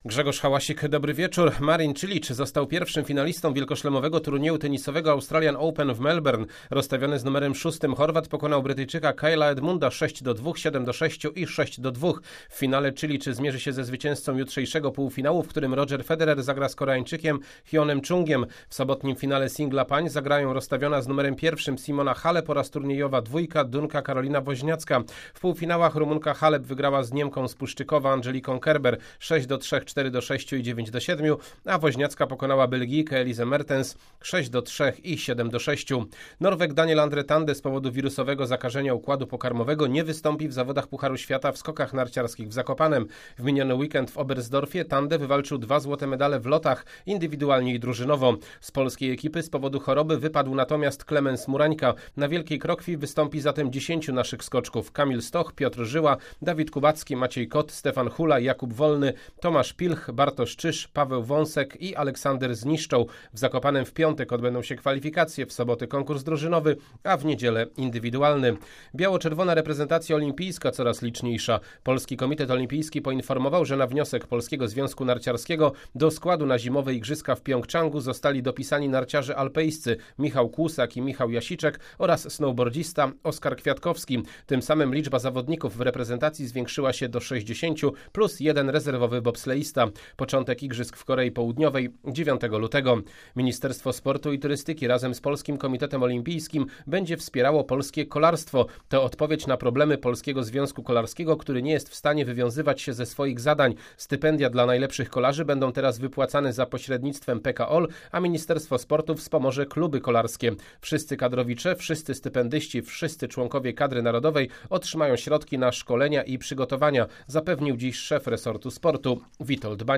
25.01 serwis sportowy godz. 19:05